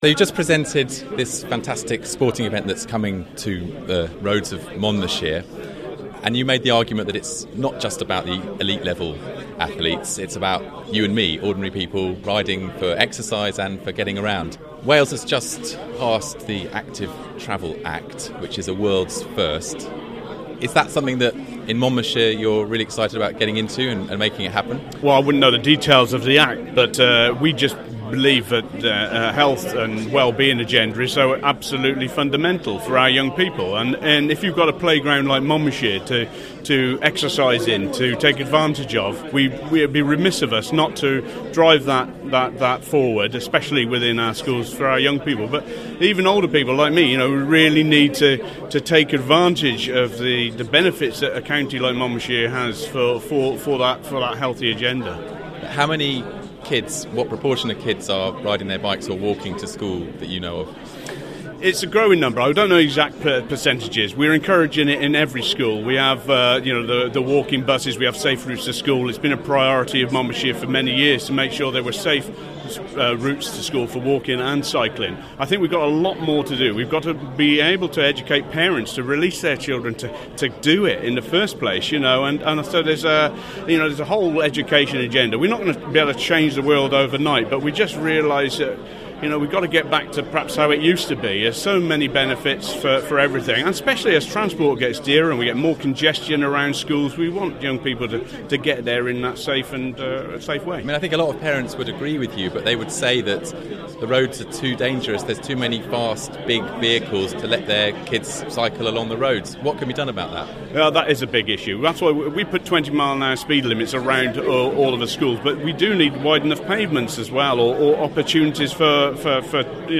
Recorded 18 March 2014 at the press launch of the British National Road Race Championships to be held in Monmouthshire in June 2014.